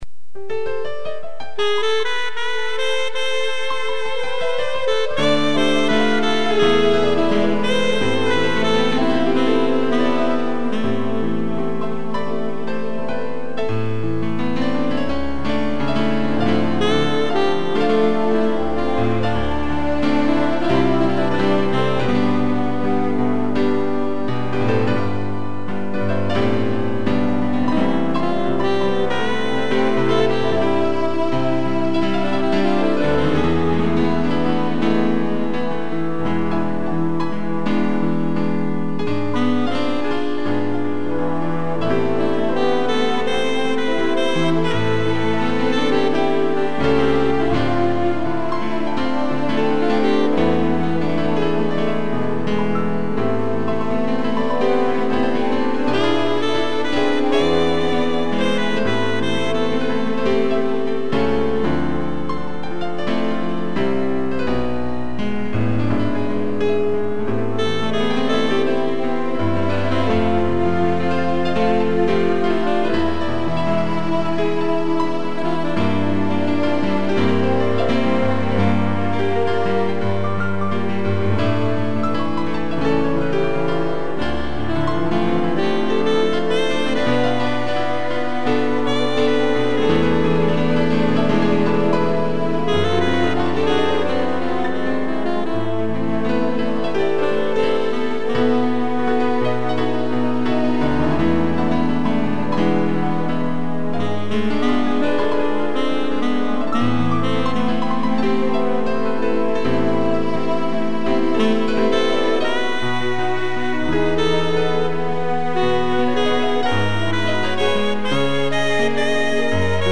2 pianos, tutti e sax
instrumental